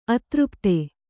♪ atřpte